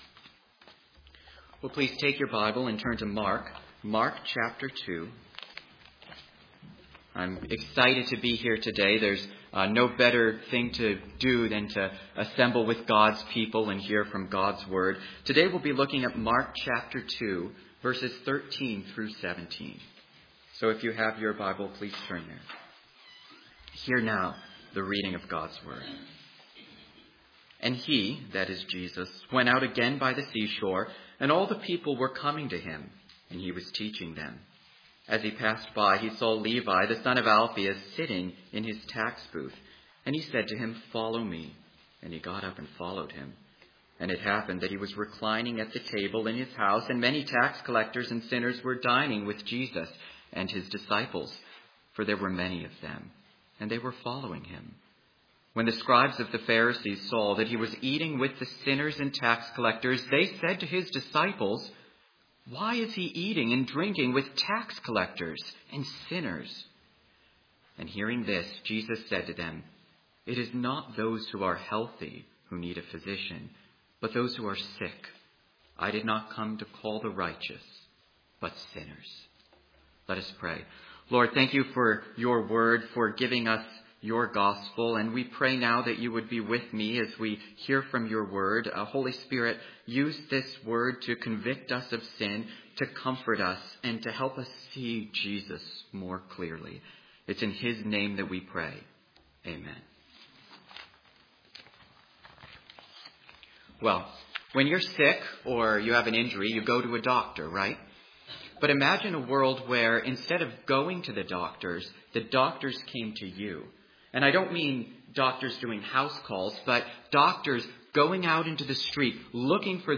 Mark 2:13-17 Service Type: Sunday Morning Mark 2:13-17 Jesus displays his authority by calling Levi.